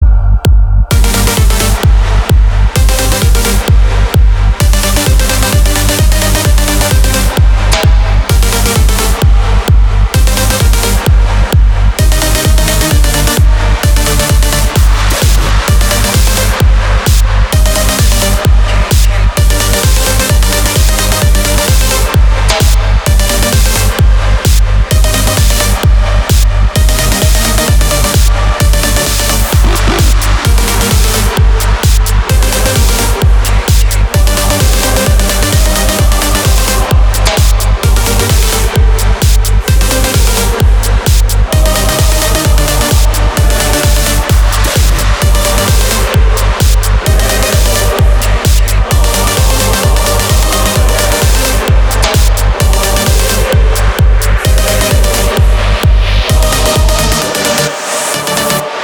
• Качество: 320, Stereo
громкие
EDM
без слов
progressive house
progressive trance